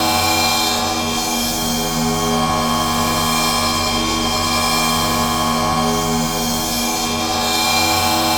BOWED GONG.wav